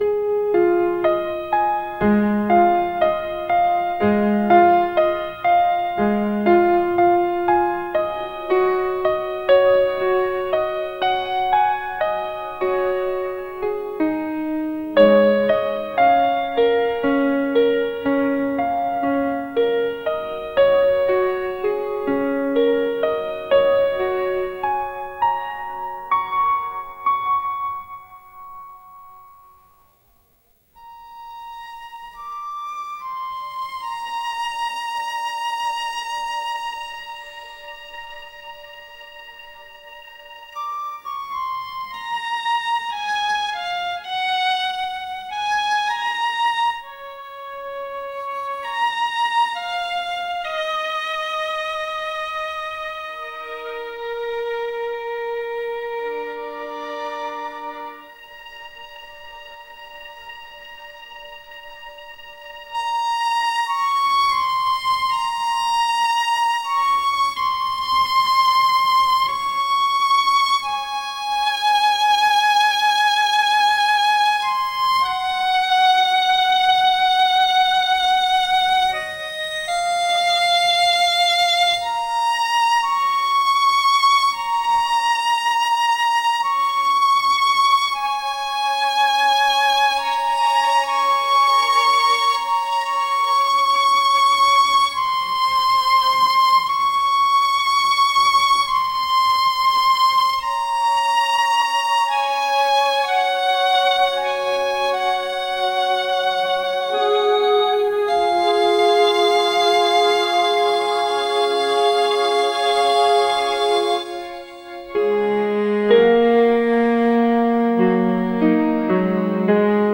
幻想的
神秘的